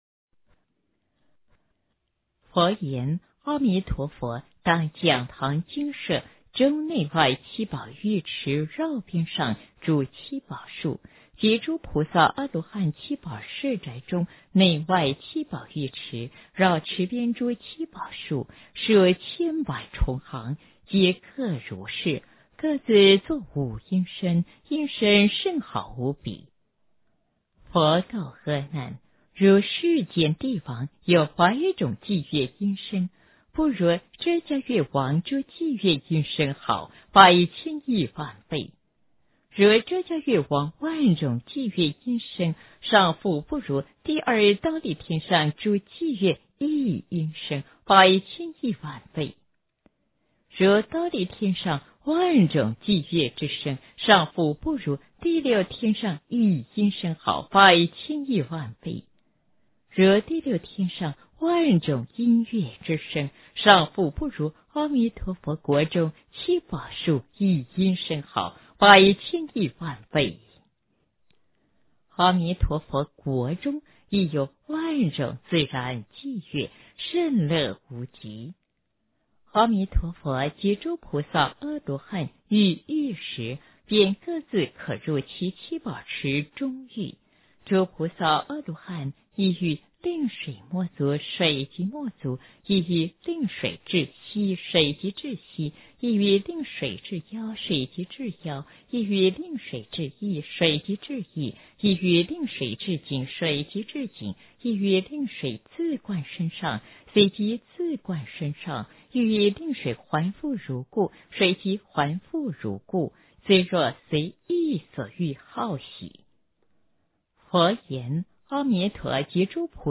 佛说阿弥陀三耶三佛萨楼佛檀过度人道经B - 诵经 - 云佛论坛